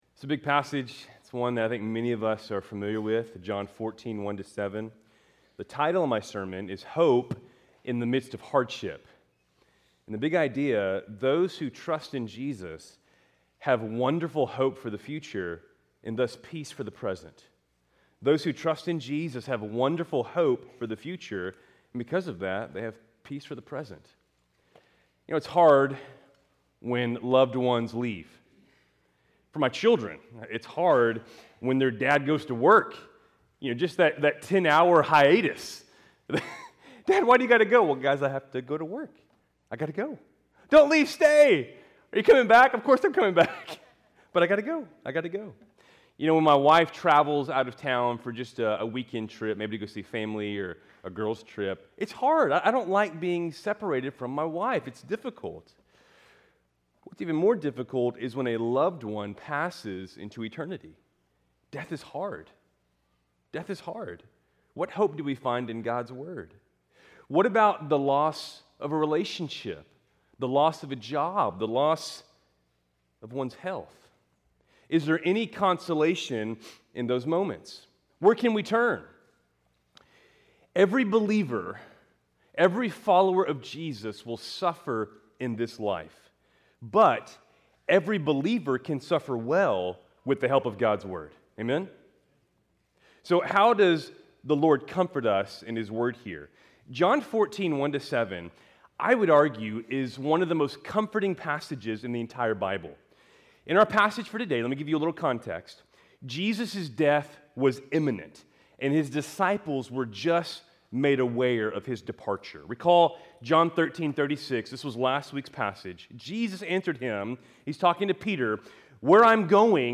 Keltys Worship Service, July 13, 2025